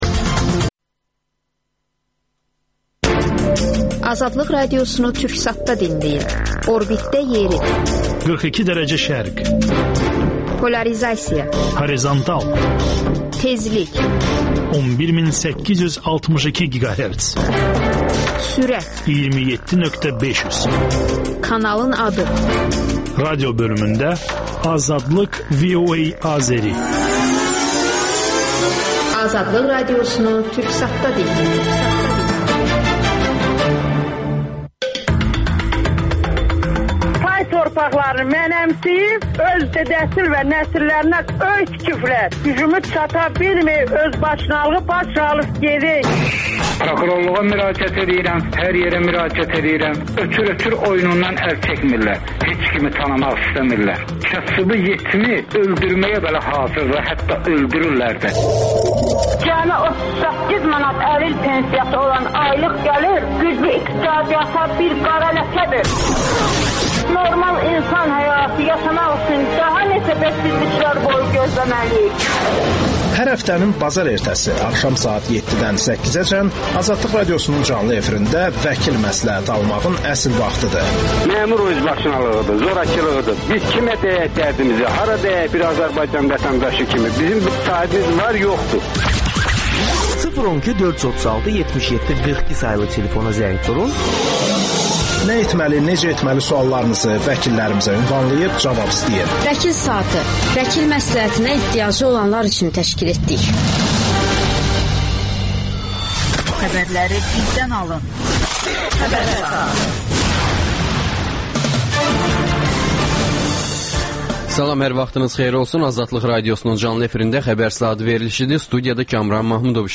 AzadlıqRadiosunun müxbirləri ölkə və dünyadakı bu və başqa olaylardan canlı efirdə söz açırlar. Günün sualı: Azərbaycanda xoşbəxtsinizmi?